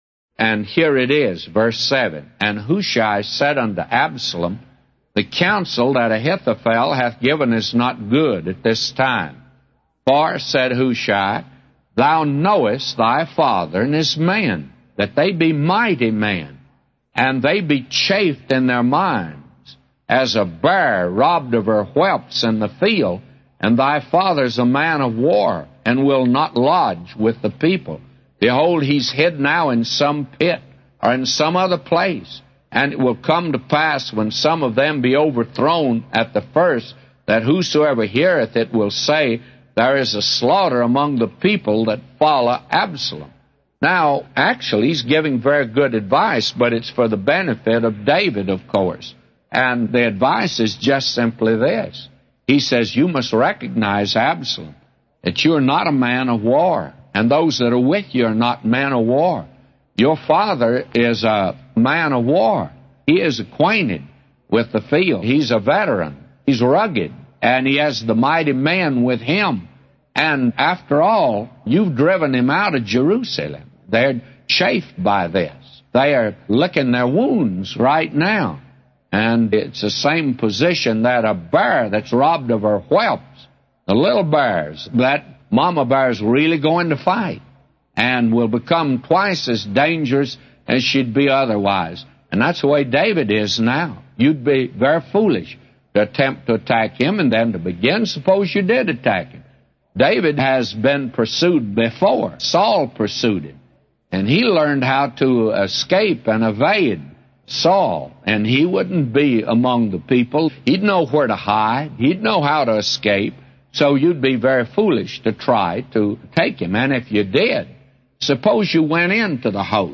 A Commentary By J Vernon MCgee For 2 Samuel 17:7-999